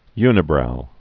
(ynĭ-brou)